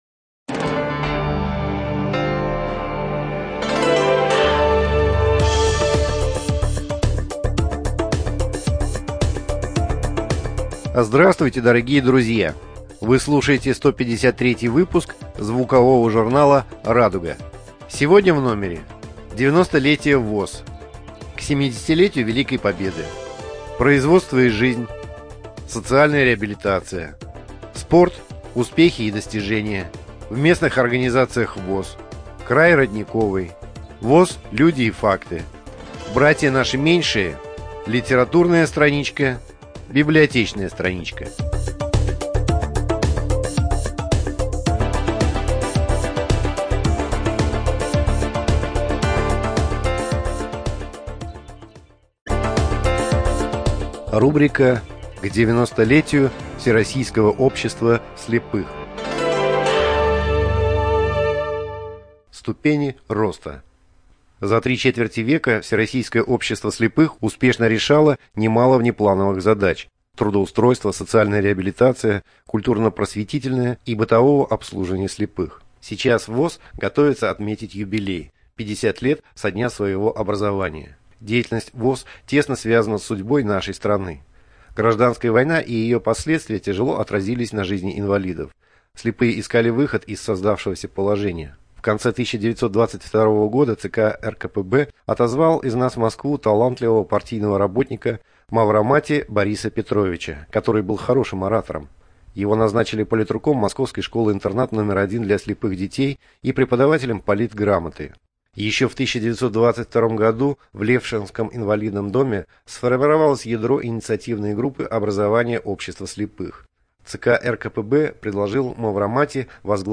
Студия звукозаписиУдмуртская республиканская библиотека для слепых